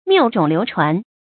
注音：ㄇㄧㄨˋ ㄓㄨㄙˇ ㄌㄧㄨˊ ㄔㄨㄢˊ
謬種流傳的讀法